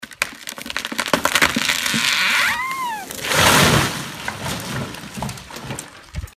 Звуки деревьев